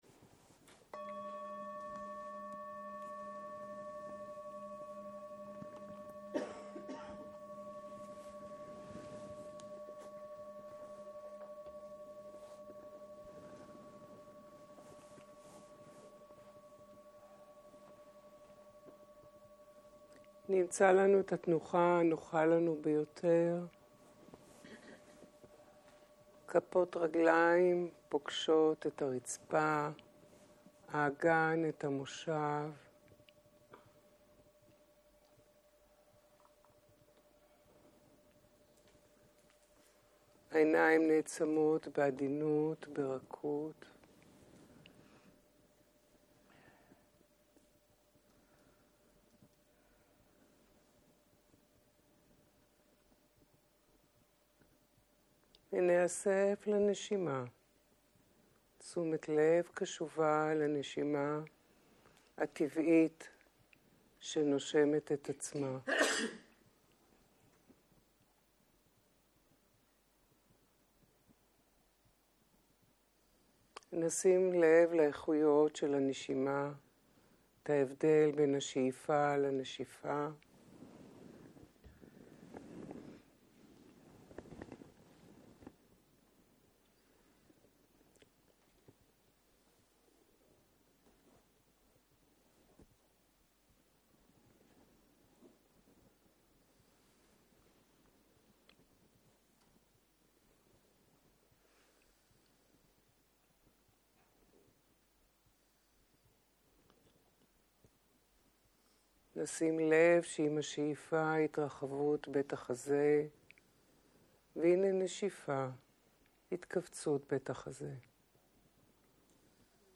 15.03.2023 - יום 1 - ערב - מדיטציה מונחית - הקלטה 1
Guided meditation